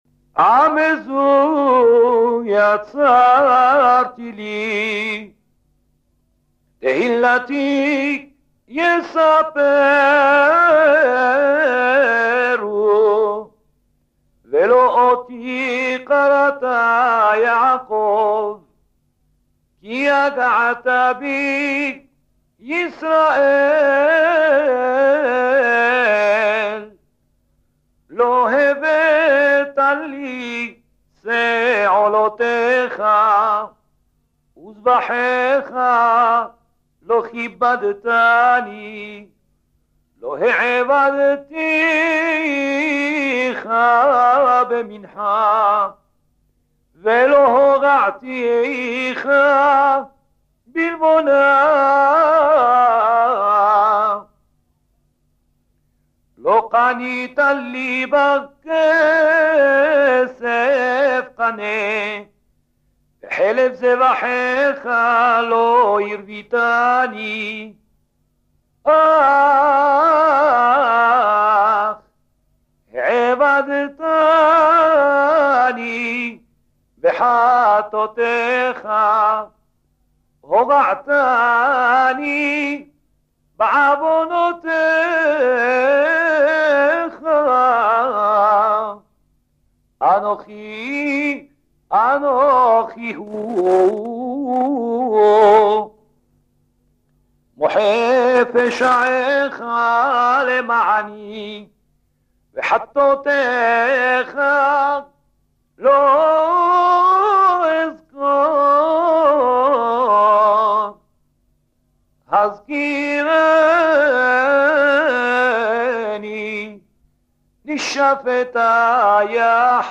The 5 box sets by Haïm Louk comprise each 6 audio cassettes featuring the lecture of the weekly section of the bible.
With a beautiful voice and great talent, he was the mentor of the master of Jewish Moroccan liturgy, Rabbi David Bouzaglo.
k7_1301f_haftara-de-la-semaine-vayikra.mp3